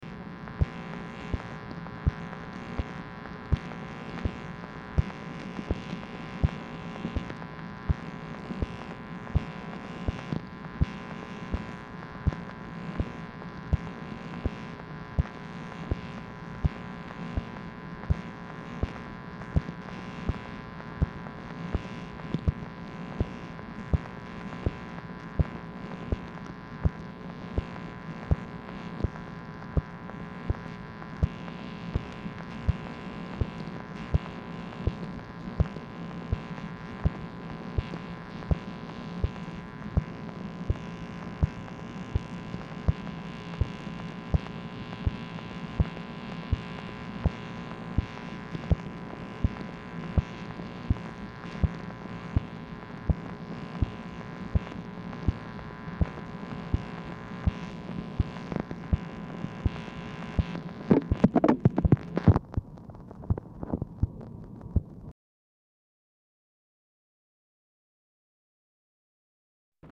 Telephone conversation # 13404, sound recording, MACHINE NOISE, 9/2/1968, time unknown | Discover LBJ
Format Dictation belt
LBJ Ranch, near Stonewall, Texas